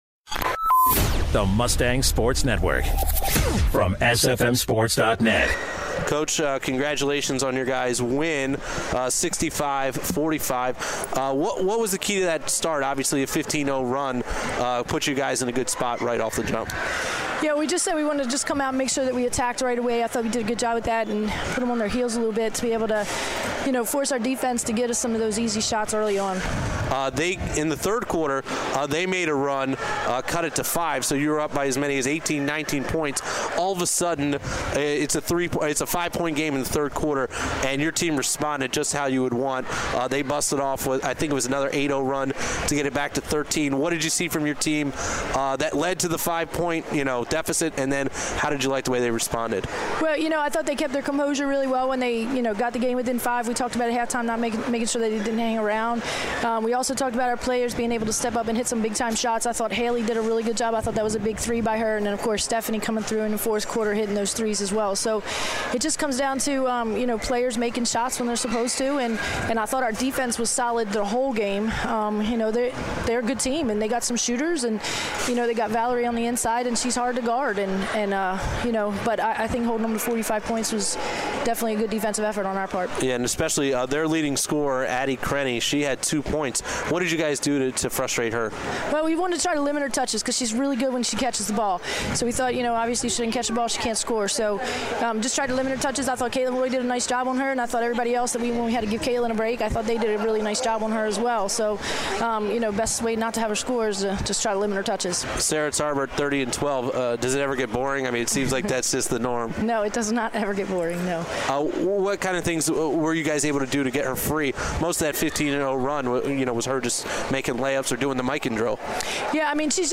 1/9/16: Stevenson Women's Basketball Post Game Show